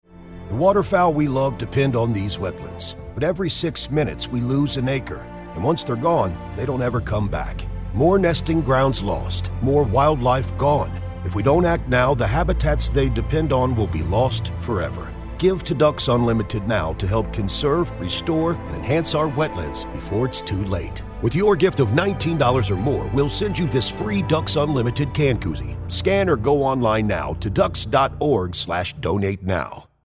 American Voice Over Talent
Adult (30-50) | Older Sound (50+)
The Voice Realm represents professional and affordable American and Canadian voice talent with authentic North American accents suited to international voice castings, from small jobs to international campaigns.
Our voice over talent record in their professional studios, so you save money!